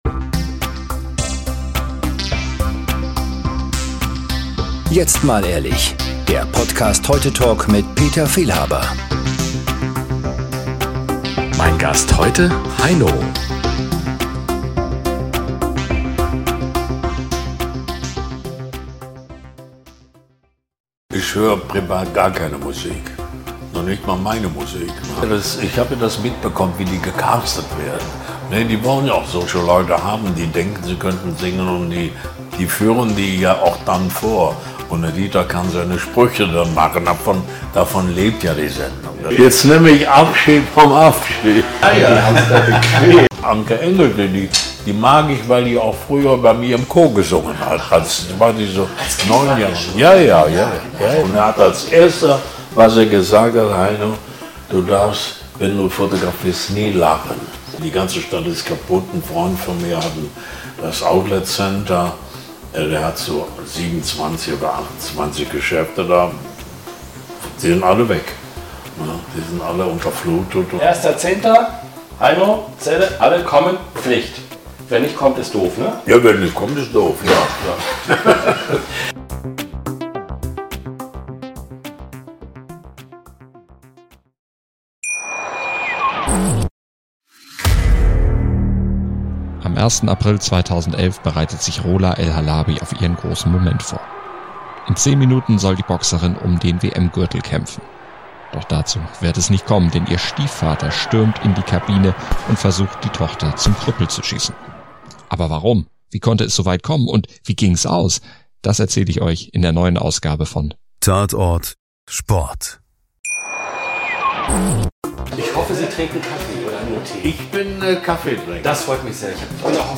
"(K)ein schöner Land" - Schlagfertig, unterhaltsam aber auch nachdenklich zeigt sich Heino.